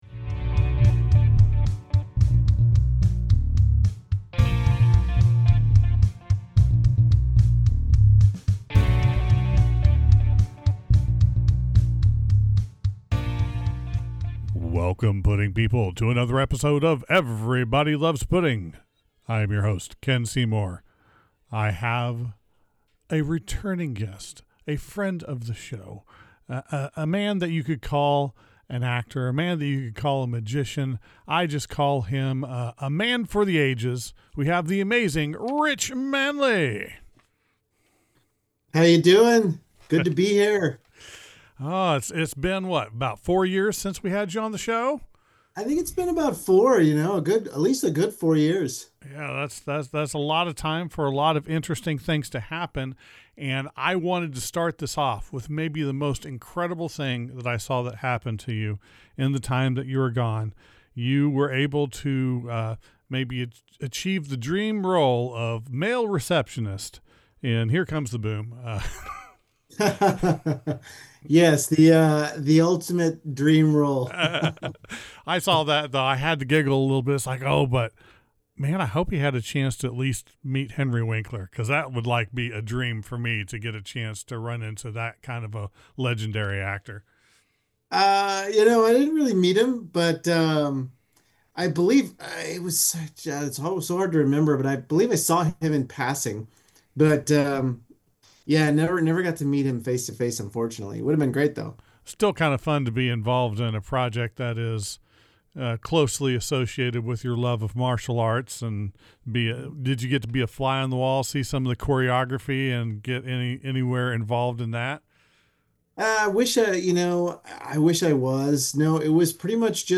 8.01: Interview